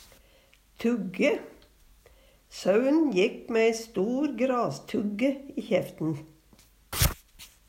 tugge - Numedalsmål (en-US)